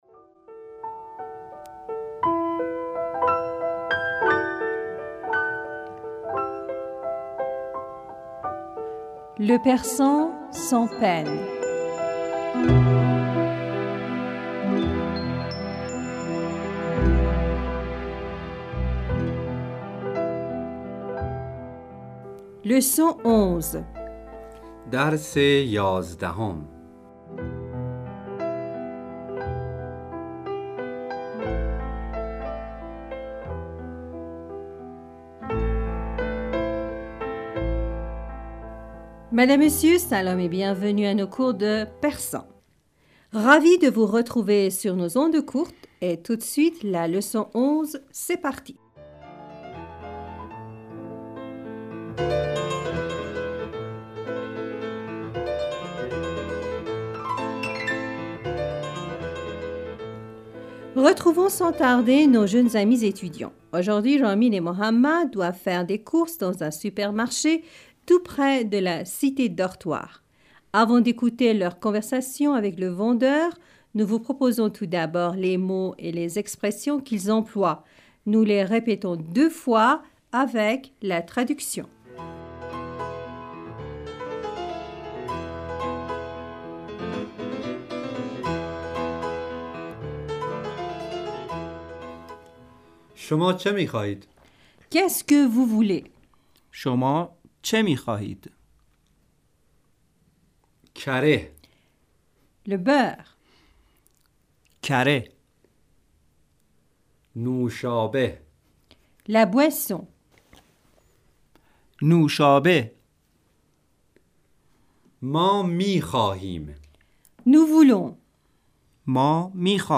Nous les répétons deux fois, avec la traduction.
Nous répétons chaque phrase, deux fois, avec la traduction.